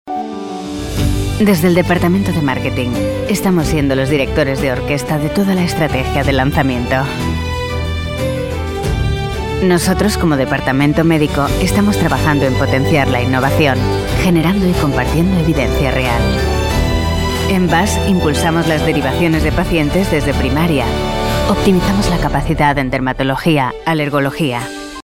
Veelzijdig, Zakelijk, Commercieel, Vriendelijk, Natuurlijk
Corporate